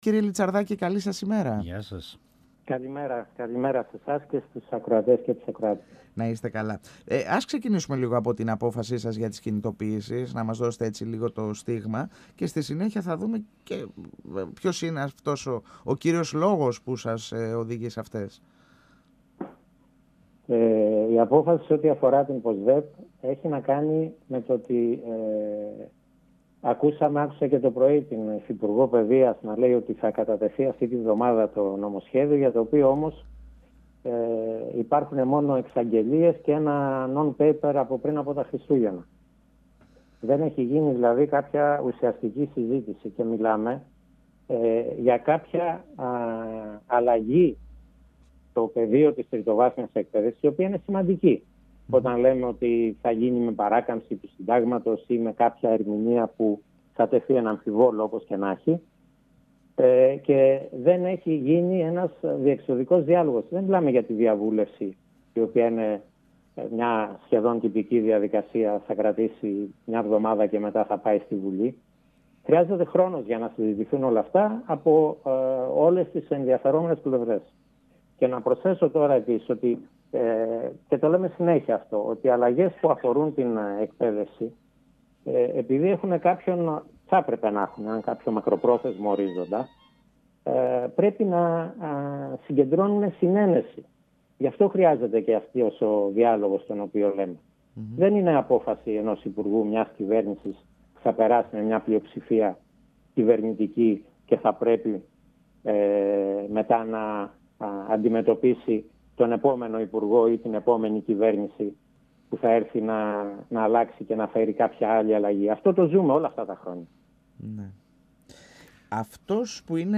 Δεν είναι απόφαση ενός υπουργού ή μιας κυβέρνησης» υπογράμμισε. 102FM Εδω και Τωρα Συνεντεύξεις ΕΡΤ3